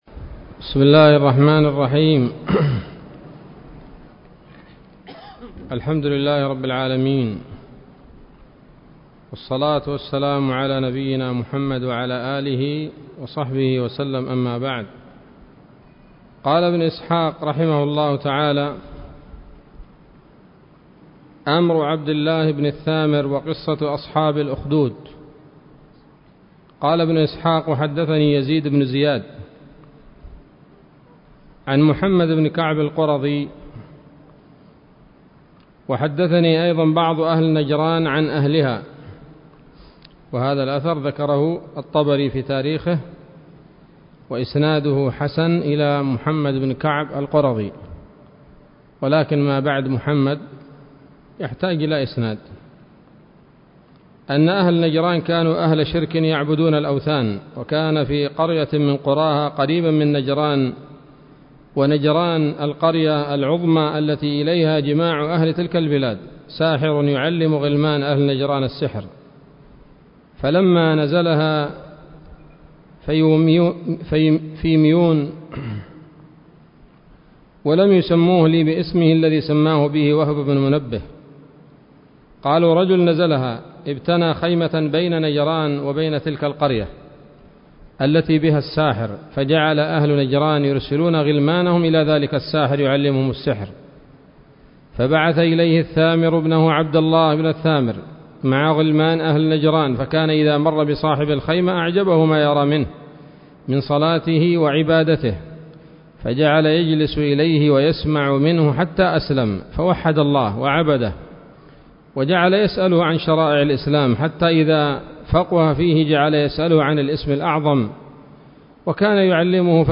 الدرس الخامس من التعليق على كتاب السيرة النبوية لابن هشام